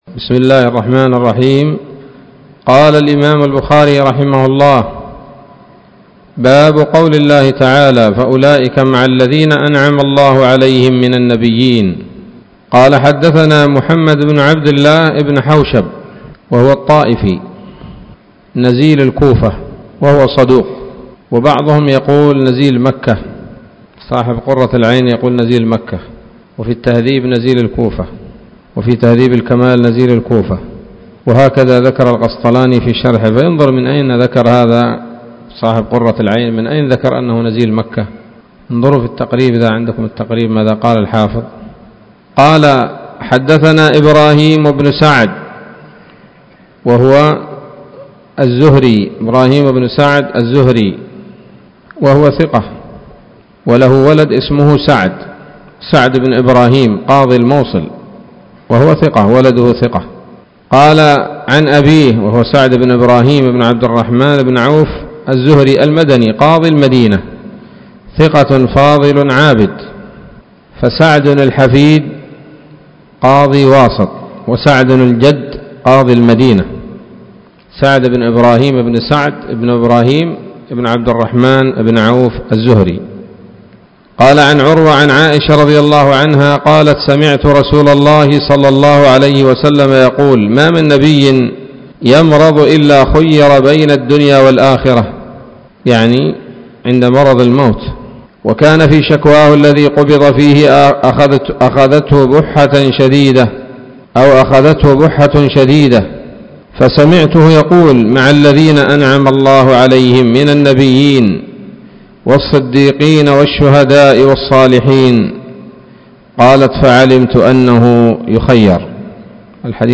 الدرس الثالث والسبعون من كتاب التفسير من صحيح الإمام البخاري